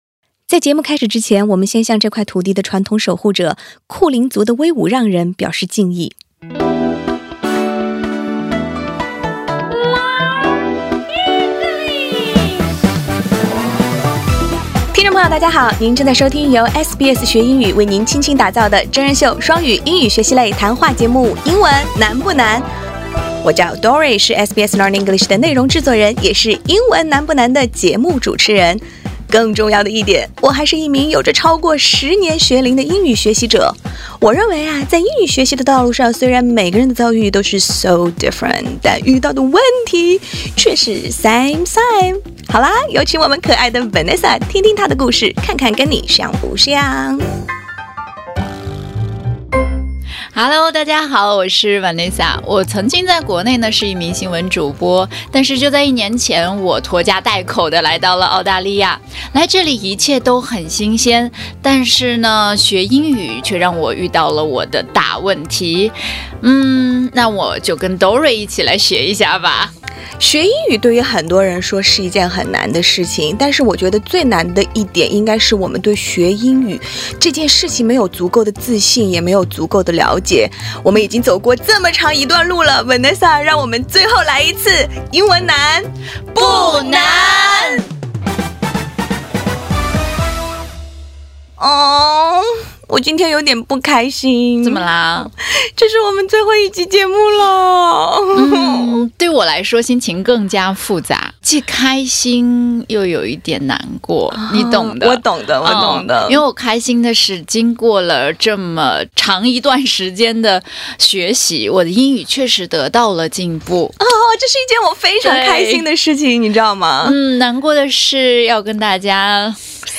This episode explains the difference between accent and pronunciation. We talk with a language expert who highlights the importance of embracing our unique accent while also improving pronunciation for better communication.